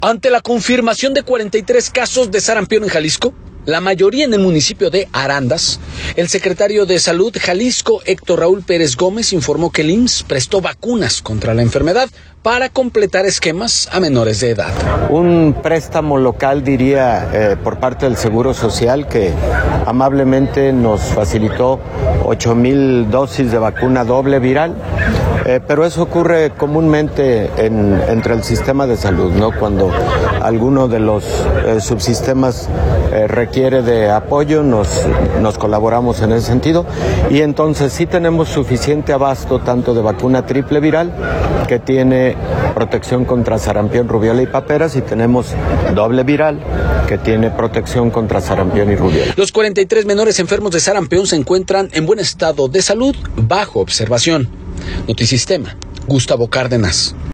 Ante la confirmación de 43 casos de sarampión en Jalisco, la mayoría en el municipio de Arandas, el secretario de Salud Jalisco, Héctor Raúl Pérez Gómez, informó que el IMSS prestó vacunas contra la enfermedad para completar esquemas a menores de edad.